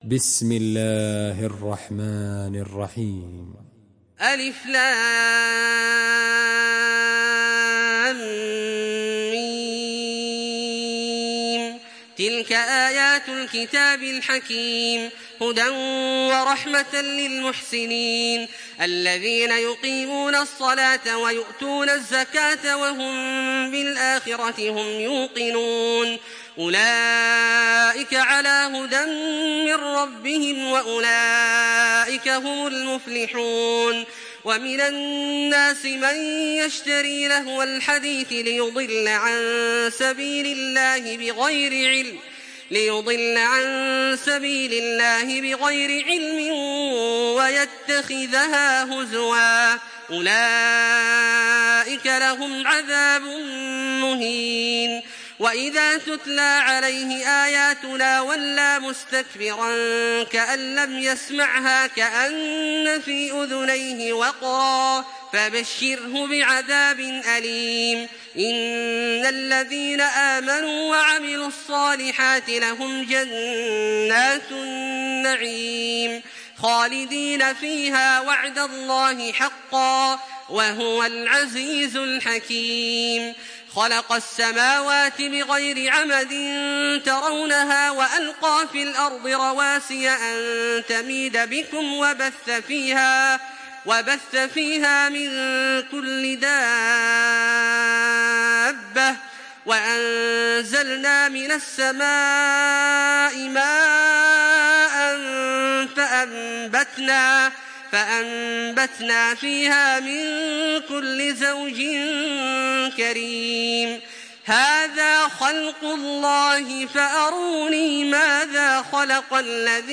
Surah Lokman MP3 in the Voice of Makkah Taraweeh 1426 in Hafs Narration
Murattal